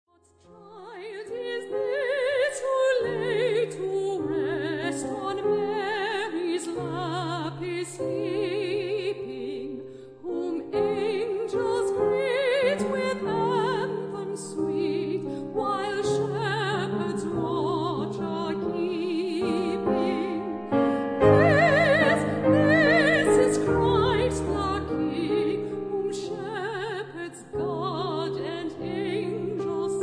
English Christmas Carol
mezzo-soprano
pianist